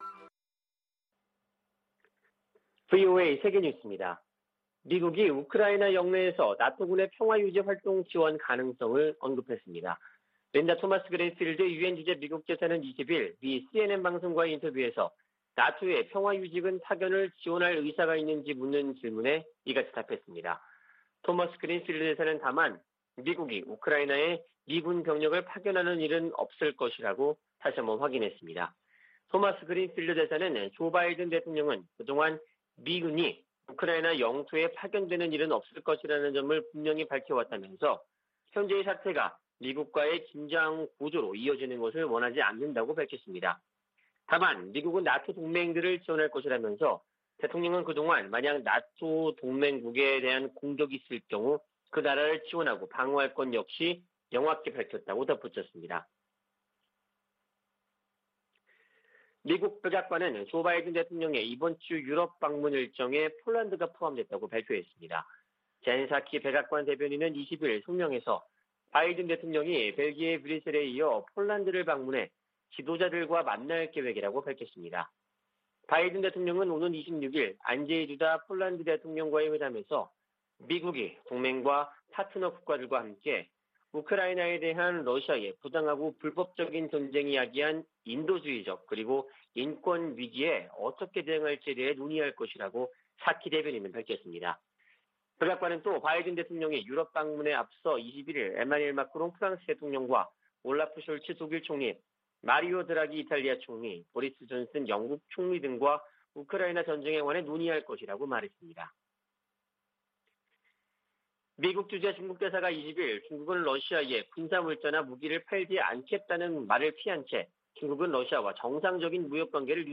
VOA 한국어 아침 뉴스 프로그램 '워싱턴 뉴스 광장' 2022년 3월 22일 방송입니다. 북한이 20일 한반도 서해상으로 방사포로 추정되는 단거리 발사체 4발을 쐈습니다. 미 국무부는 북한이 미사일 도발 수위를 높이는데 대해 모든 필요한 조치를 취할 것이라며 본토와 동맹국 방어 의지를 재확인했습니다.